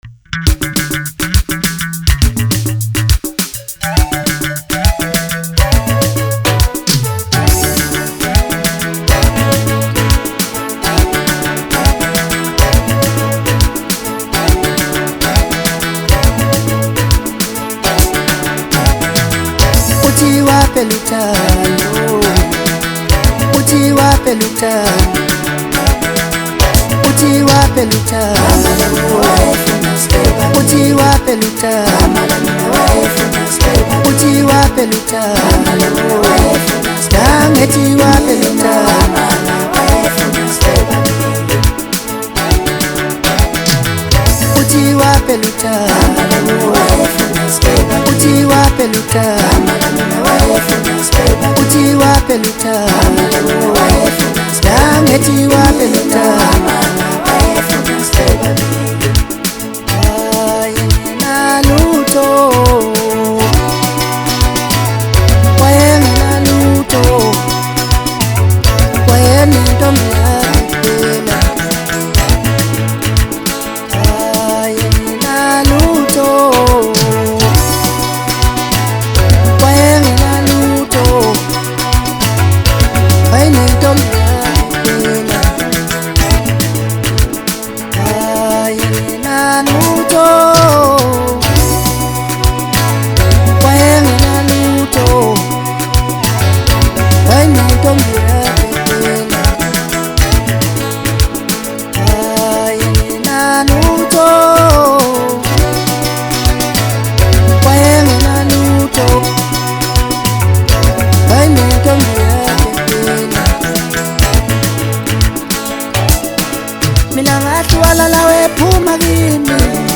Maskandi king
is a melodic tune featuring the stunning vocals